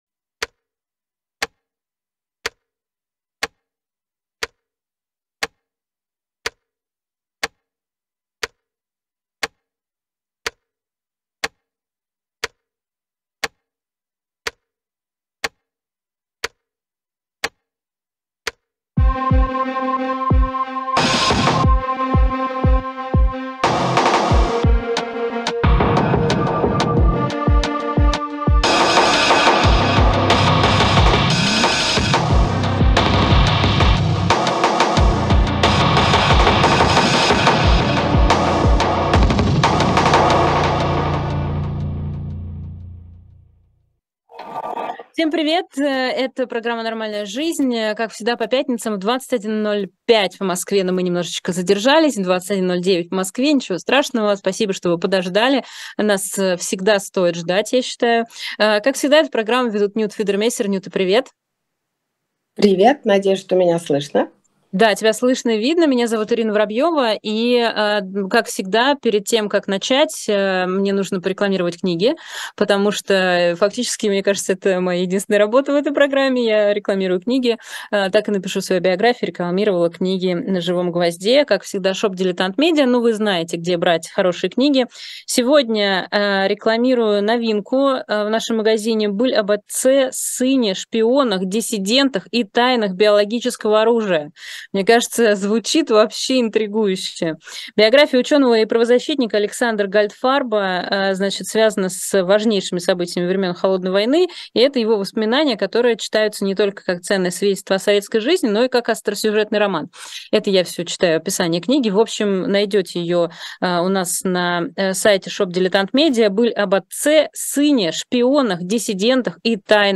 Ведущие: Ирина Воробьёва, Нюта Федермессер